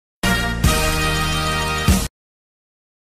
Tusch 1x_1.mp3